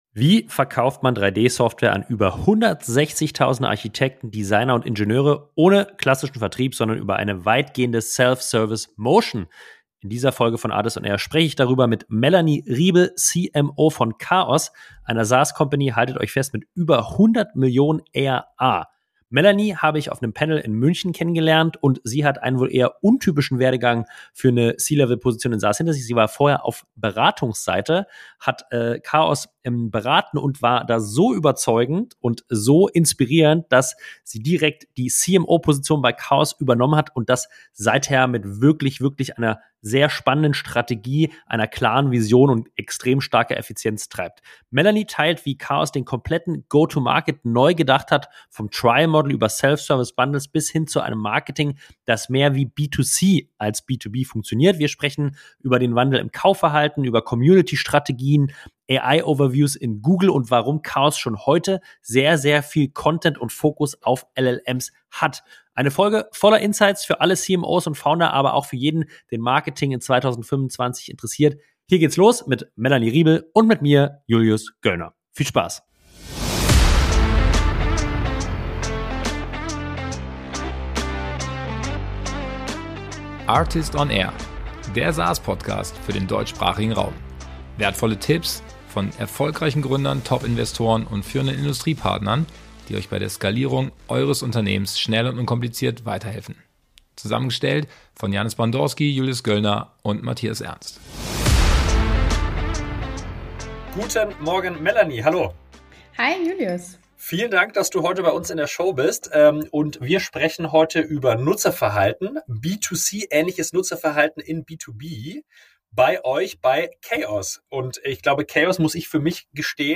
ARRtist on AIR - Meaningful conversations with Software & AI leaders « » 267: Self-Service First bei Chaos - Mit B2C ähnlicher Acquisition zu über 100 Mio.